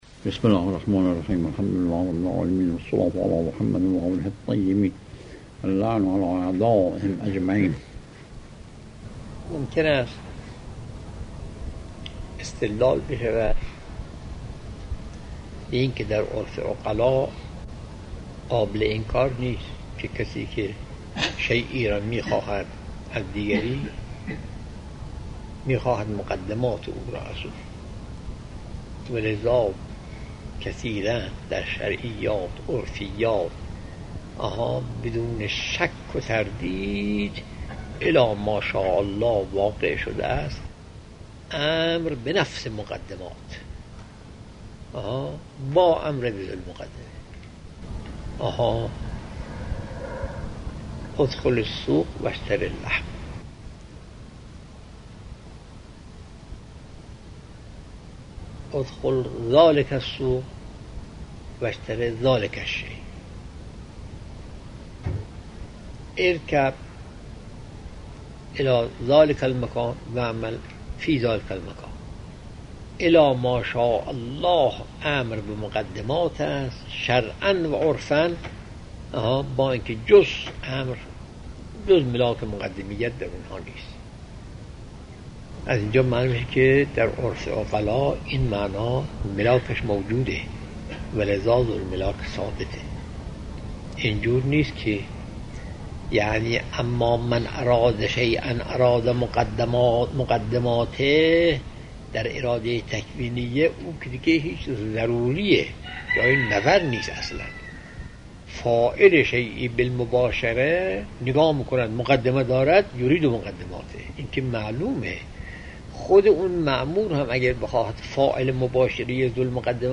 آيت الله بهجت - خارج اصول | مرجع دانلود دروس صوتی حوزه علمیه دفتر تبلیغات اسلامی قم- بیان